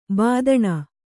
♪ bādaṇa